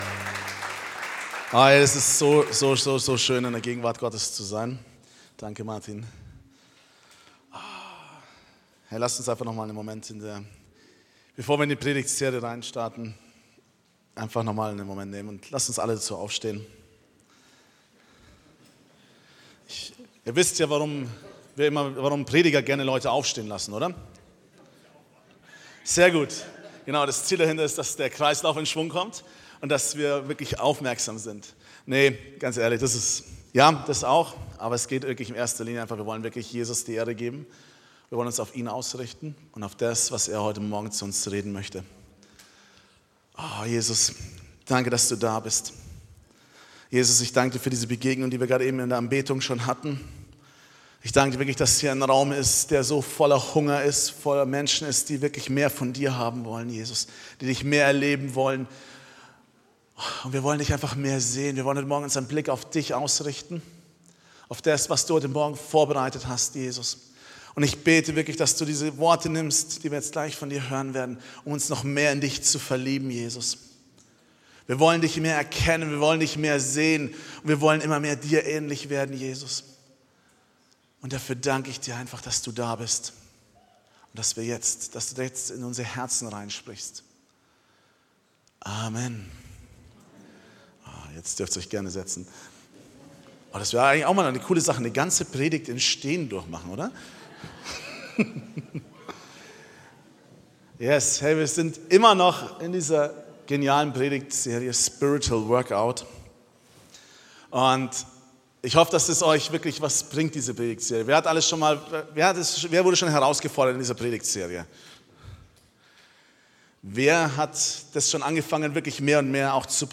Predigten - xhope